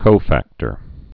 (kōfăktər)